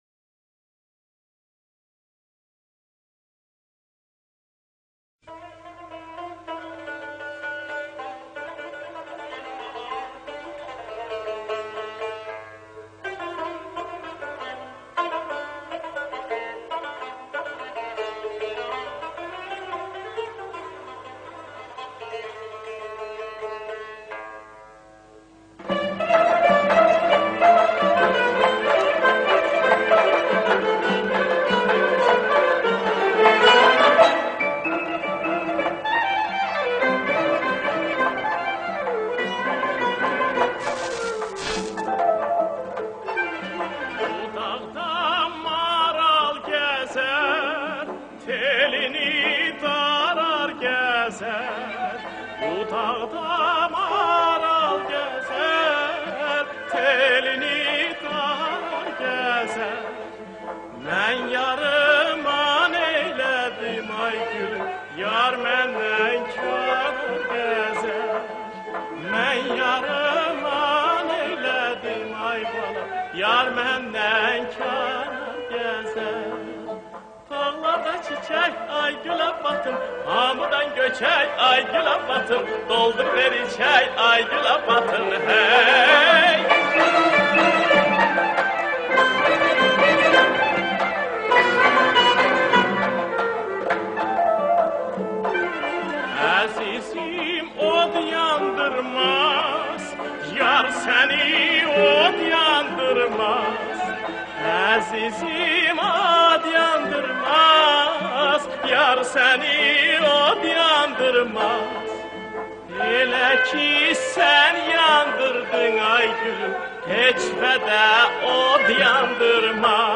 آهنگ آذربایجانی
فولکلور آذربایجانی